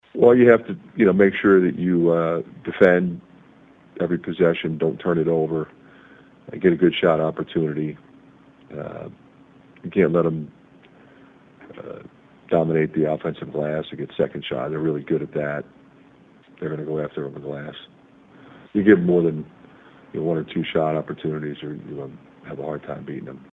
McCaffery says it will take a complete effort to have a chance.